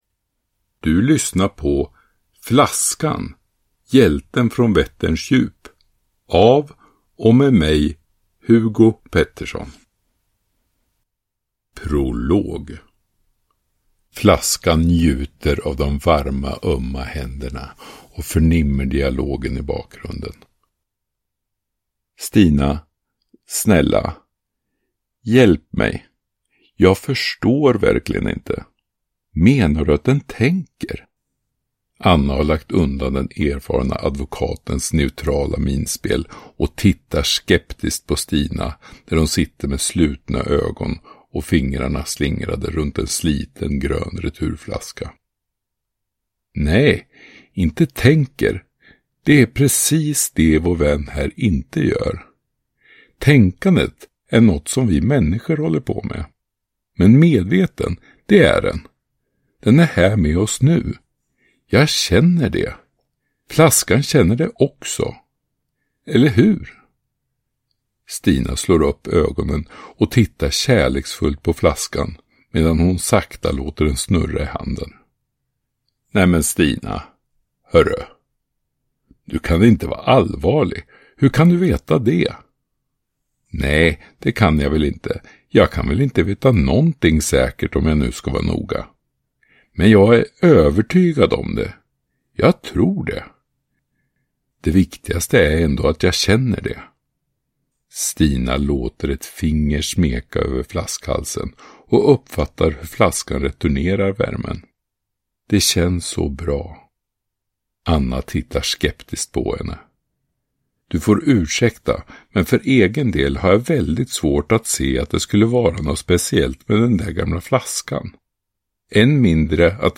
Flaskan - Hjälten från Vätterns djup – Ljudbok